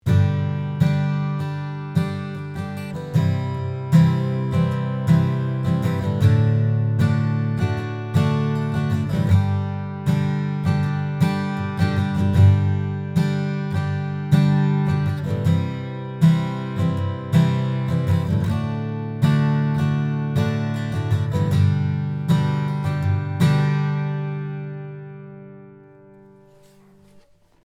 Hi, ich hatte nun schon öfters das Problem und nun gerade wieder. hab eine Akustik Gitarre aufgenommen un wenn ich den Kompressor drauf mache Pumpt es.
Die Aufnahme hat leider sehr viel Bass. Vermutlich ist das Mikro zu nah am Schalloch.